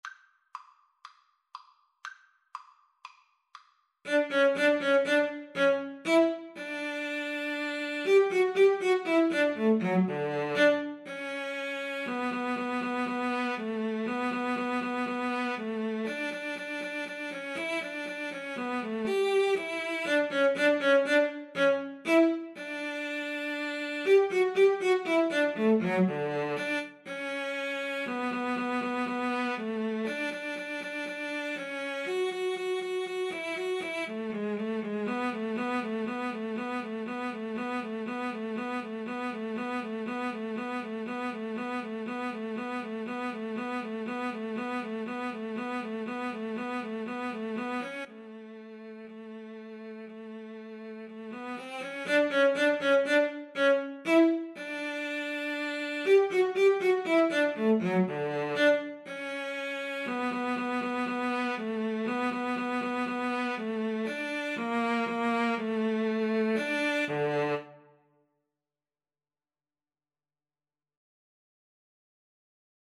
Allegro (View more music marked Allegro)
Cello Duet  (View more Intermediate Cello Duet Music)
Classical (View more Classical Cello Duet Music)